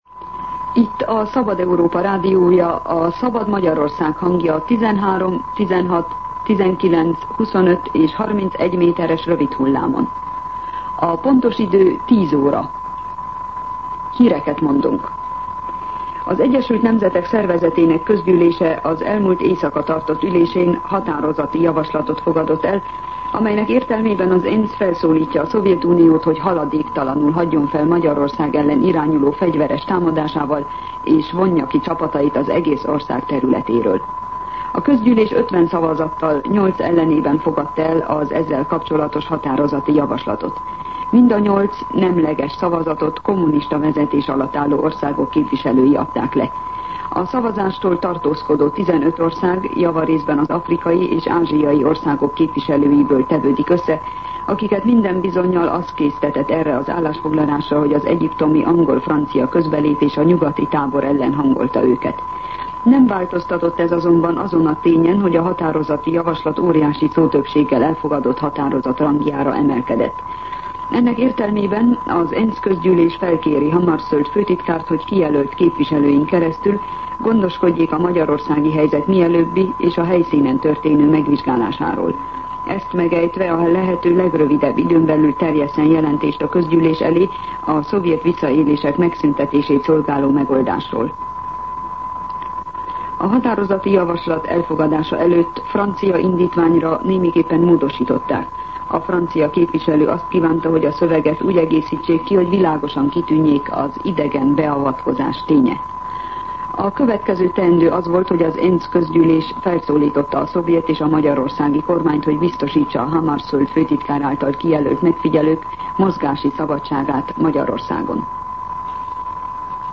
10:00 óra. Hírszolgálat